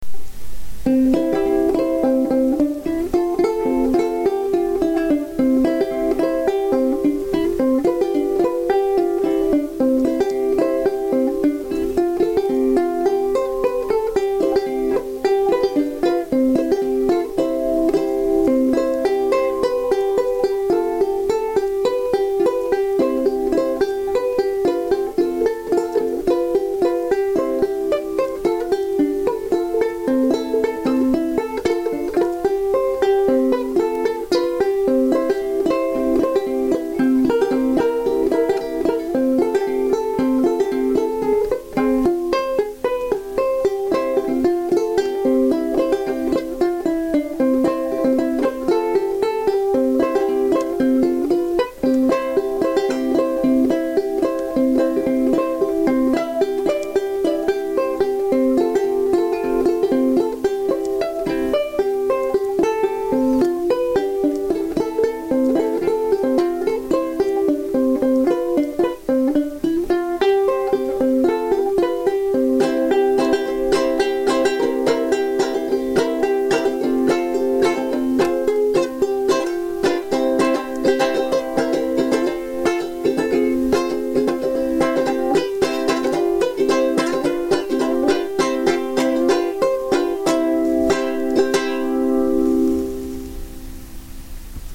Gourd banjolele improv